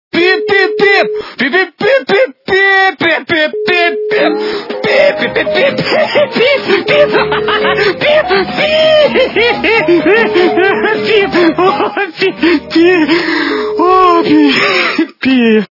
» Звуки » Будильники » Звонок для будильника - Обкуренный будильник
При прослушивании Звонок для будильника - Обкуренный будильник качество понижено и присутствуют гудки.